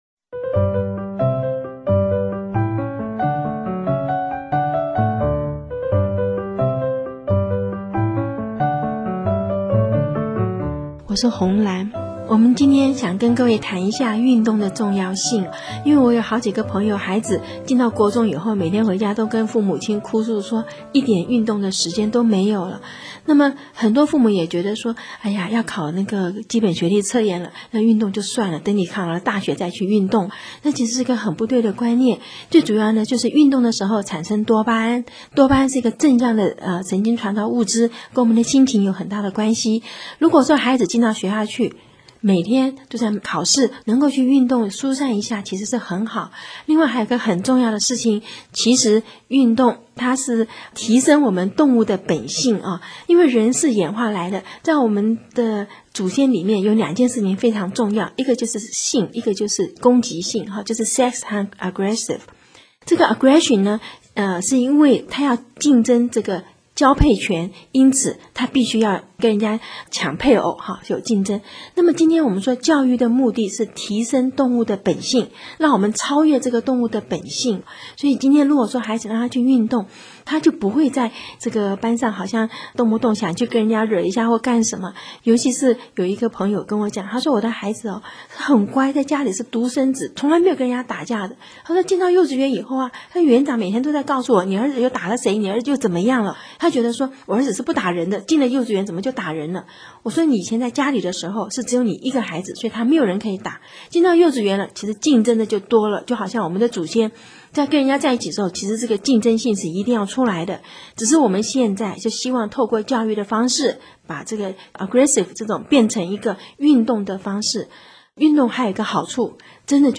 有聲書第二輯